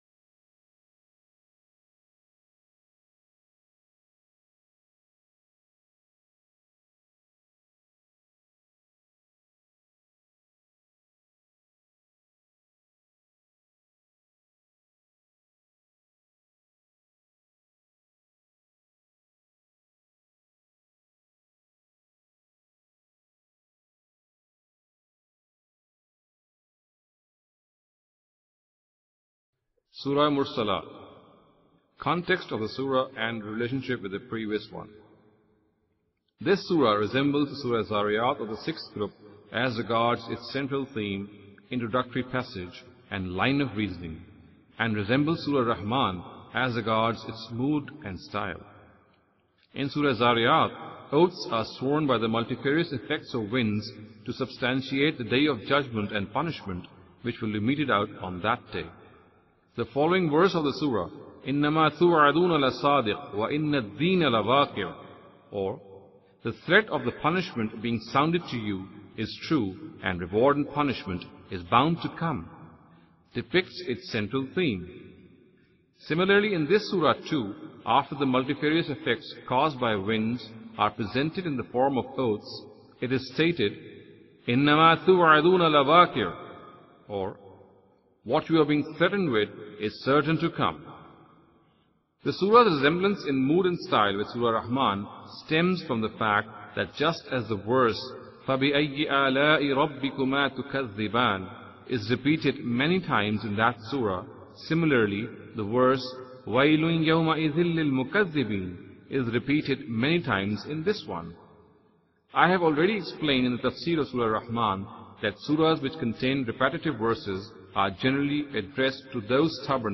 Imam Amin Ahsan Islahi's Dars-e-Qur'an.